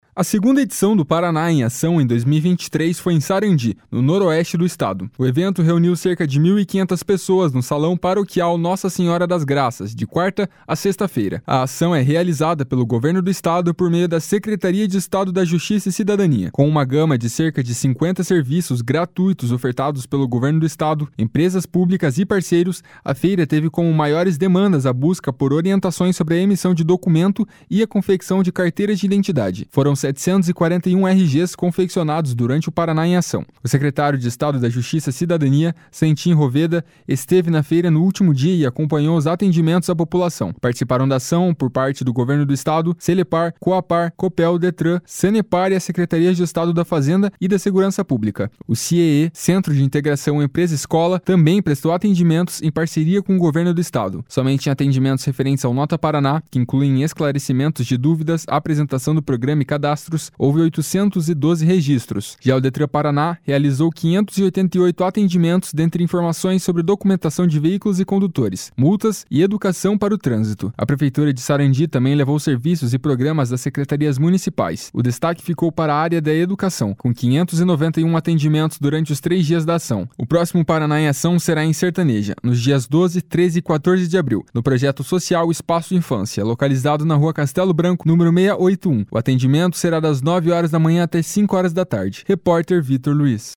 FEIRA SARANDI.mp3